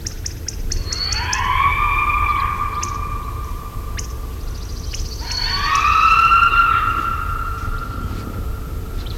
Gray fox
(Uroyon cinereoargenteus)
Common-Gray Fox-edit.mp3